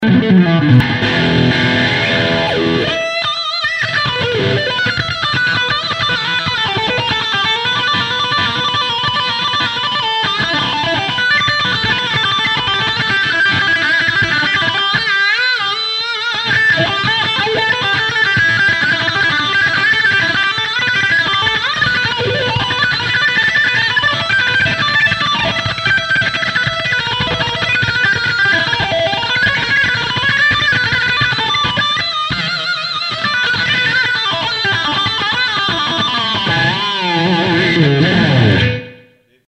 To ensure the amp’s true voice shines through, recordings were made using a Shure SM57 mic on a Marshall cabinet, alongside a direct line from the amp’s balanced SM57 mic sim output.
We kept effects minimal to let the amp speak for itself — just a hint of reverb, light delay, and a short wah pedal section.”
The-Sabre-low-ovd-wha-demo.mp3